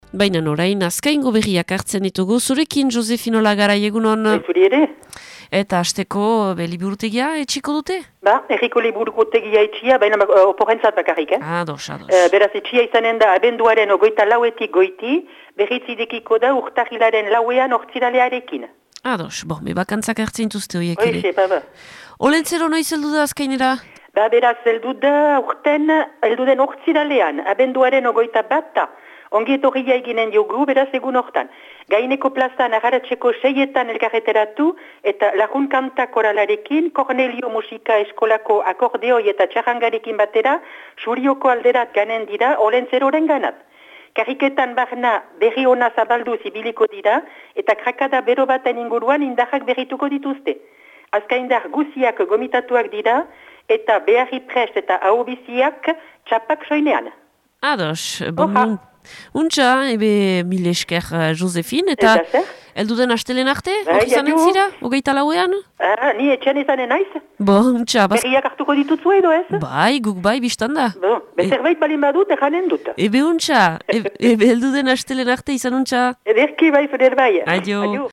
Abenduaren 17ko Azkaingo berriak